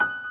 piano_last23.ogg